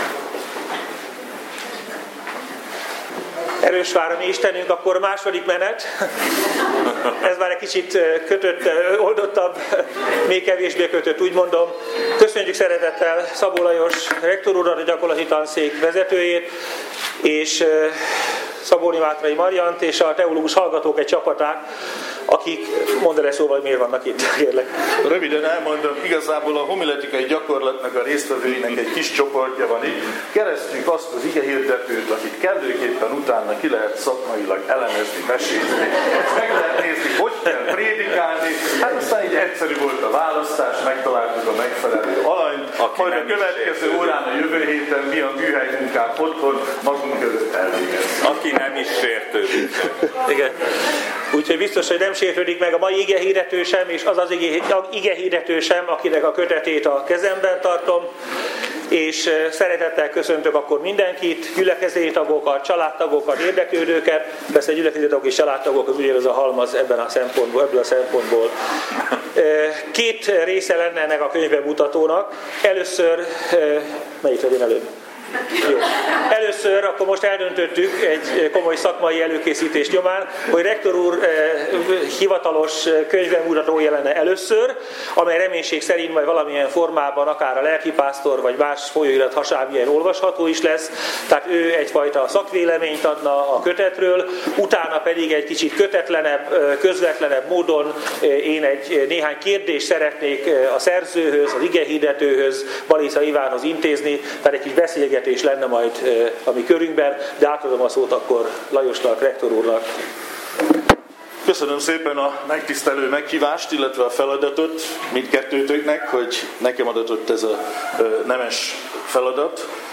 Ezt követően pedig a kötet megjelenését támogató Északi Evangélikus Egyházkerület püspöke, dr. Fabiny Tamás beszélgetett a szerzővel többek között életpályájáról, szolgálatáról, igehirdetési gyakorlatáról és a könyvben szereplő prédikációk keletkezéséről.